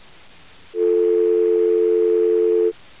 beep.wav